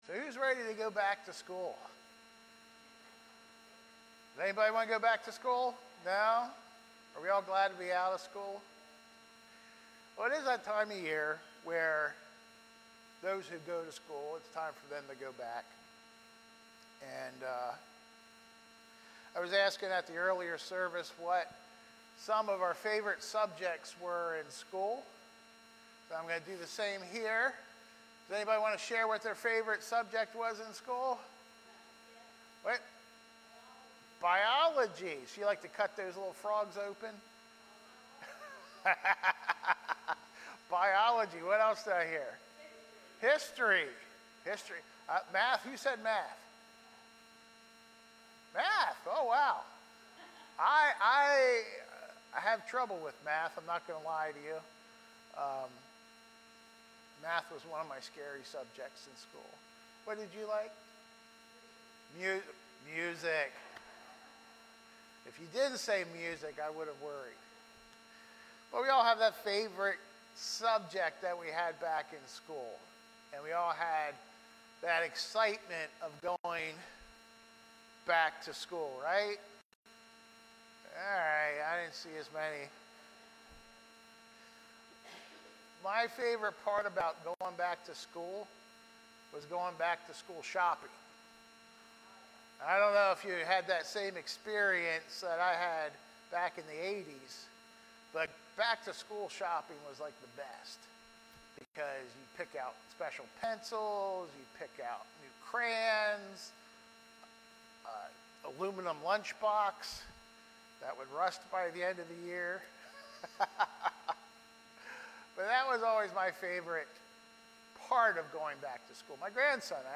Sermons | Columbia Church of God